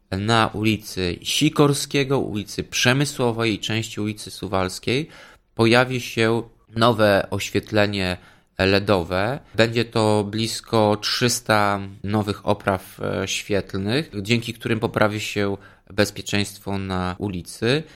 Tomasz Andrukiewicz – prezydent Ełku